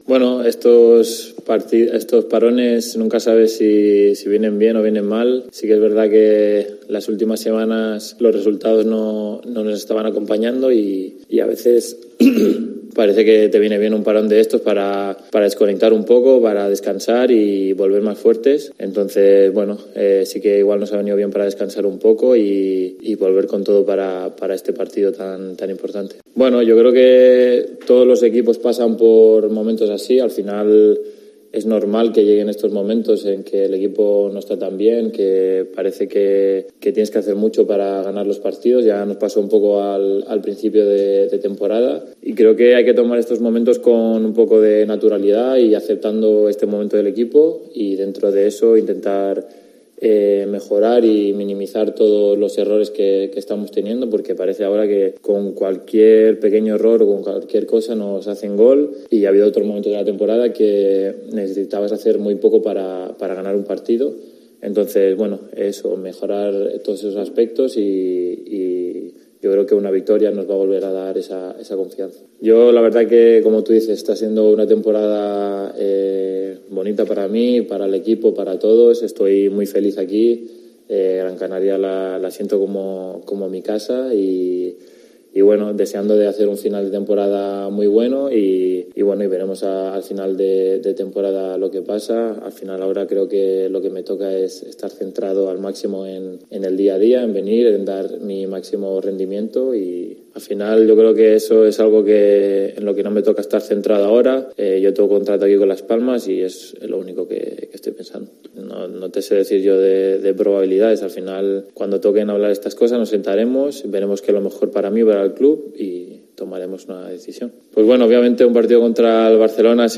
Sobre el encuentro y el momento en que se encuentran ambos equipos habló el lateral zurdo del equipo amarillo, el catalán Sergi Cardona en rueda de prensa prievio a la jornada de entrenamiento del equipo: "Obviamente un partido contra el Barça siempre es complicado.